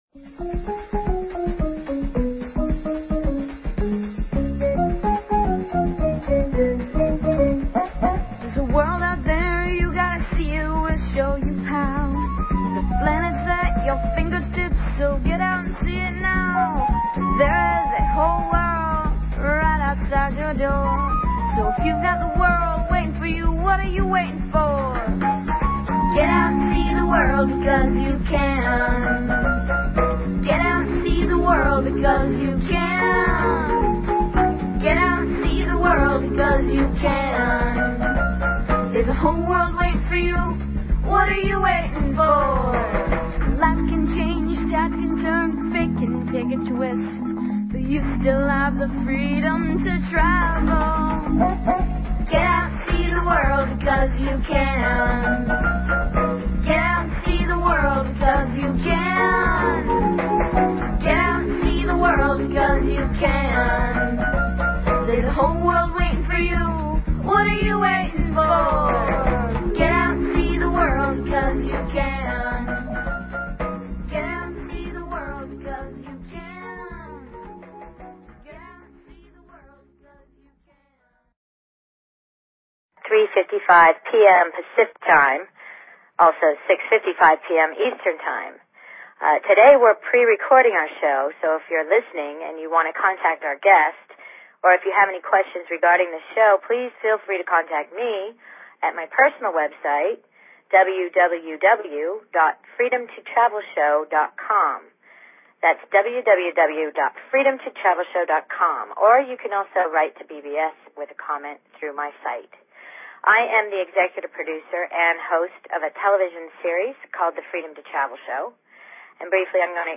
Talk Show Episode, Audio Podcast, Freedom_To_Travel and Courtesy of BBS Radio on , show guests , about , categorized as